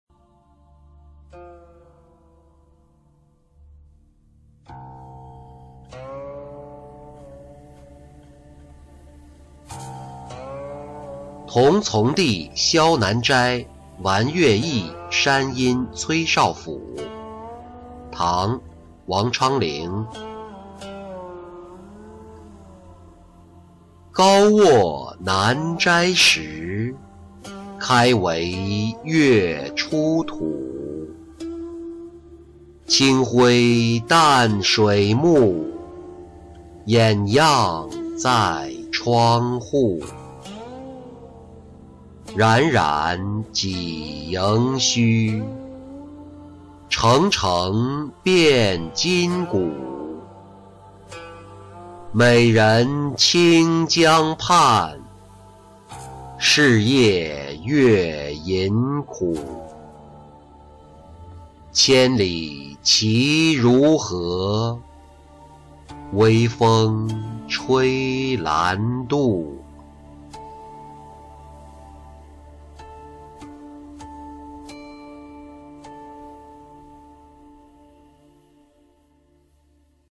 同从弟南斋玩月忆山阴崔少府-音频朗读